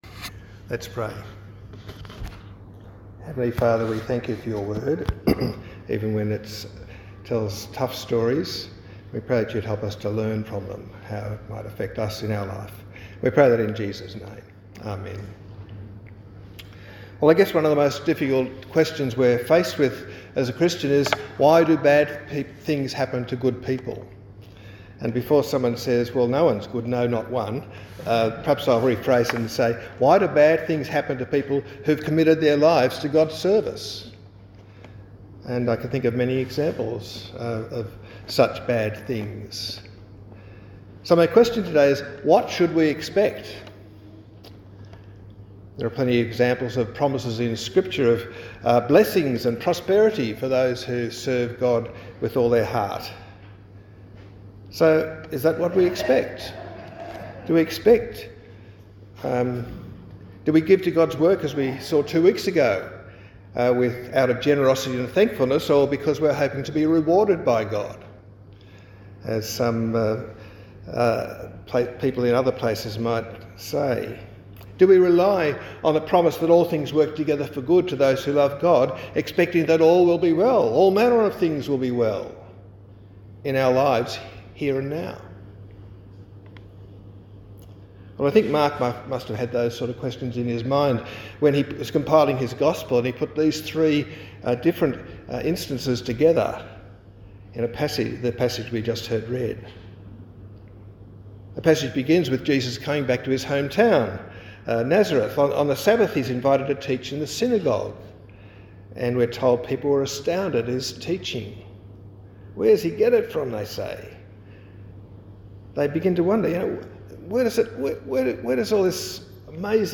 Sermons, etc.